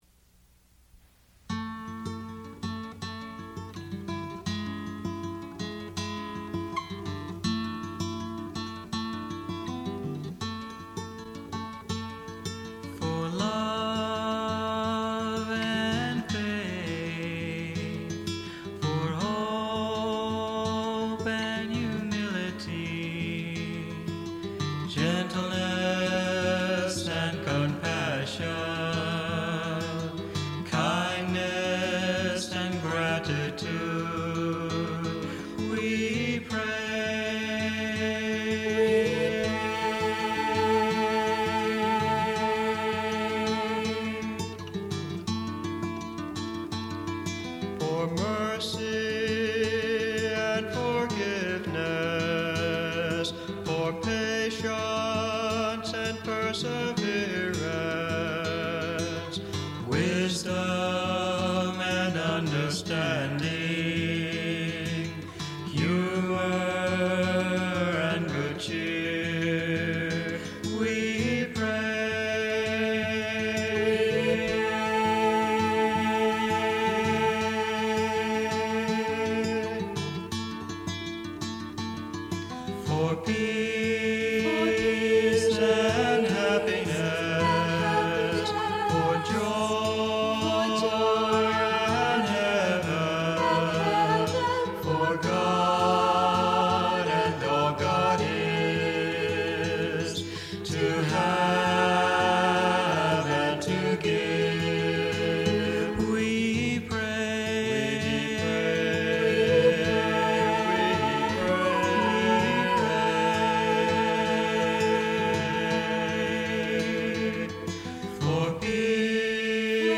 vocals, guitar
vocals, piano
vocals, percussions
Recorded 1986 in Virginia Beach, Virginia